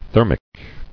[ther·mic]